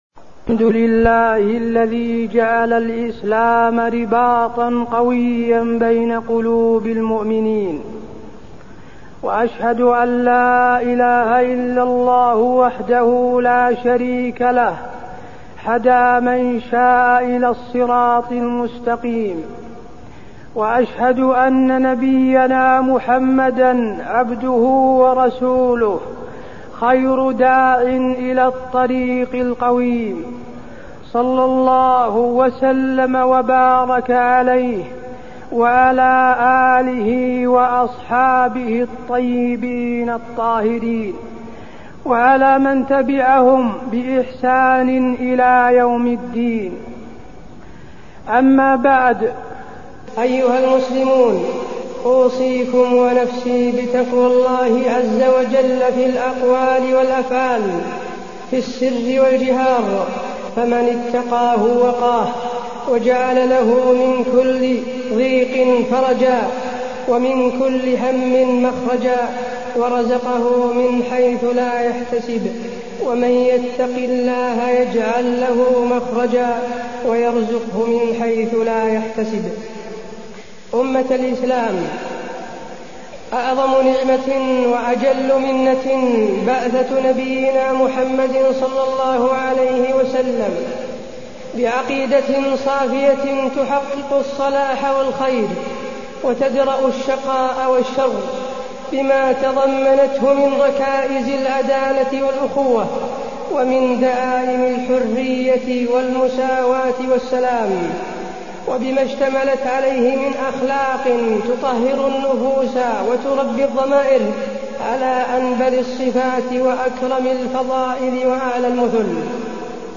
تاريخ النشر ٢٥ رجب ١٤٢٢ هـ المكان: المسجد النبوي الشيخ: فضيلة الشيخ د. حسين بن عبدالعزيز آل الشيخ فضيلة الشيخ د. حسين بن عبدالعزيز آل الشيخ الإعتصام بالله عز وجل